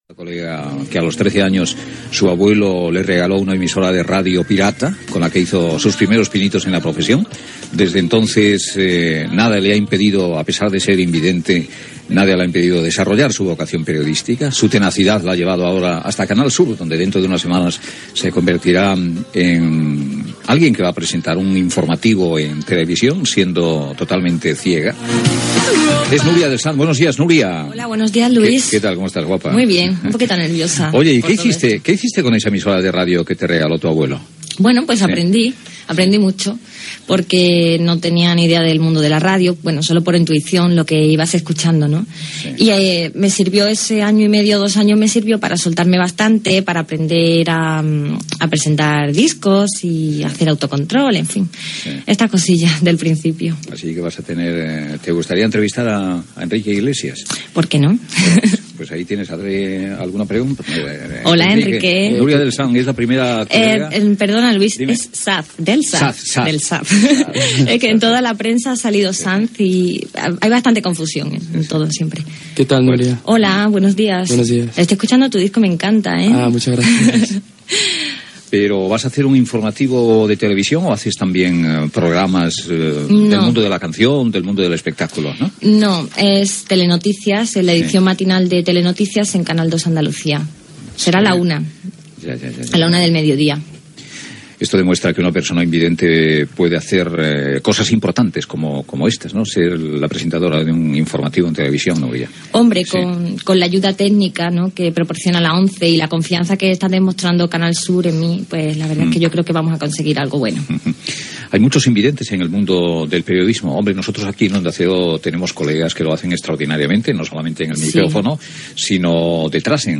Entrevista
Bons desitjos del cantant Enrique Iglesias que està a l'estudi
Info-entreteniment